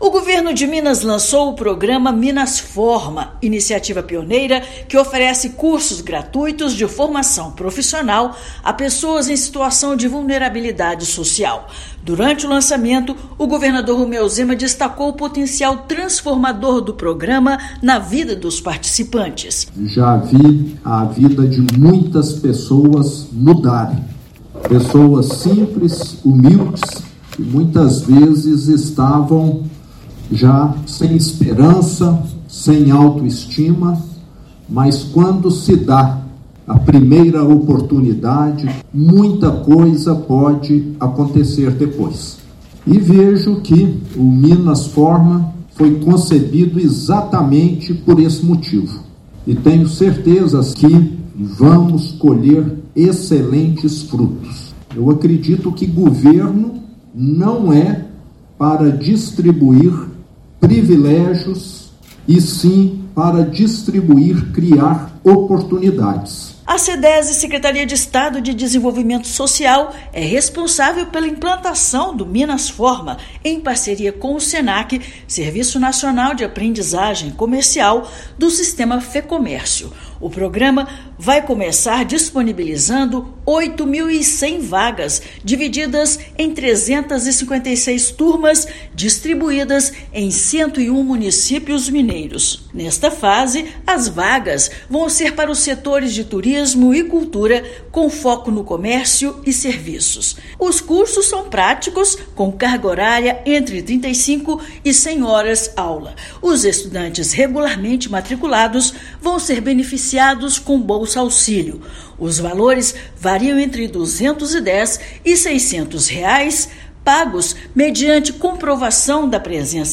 Minas Forma oferecerá cursos para pessoas em situação de vulnerabilidade social e primeiras vagas são direcionadas aos setores turismo, comércio e cultura, na área de serviços, visando geração de emprego e renda para os mineiros. Ouça matéria de rádio.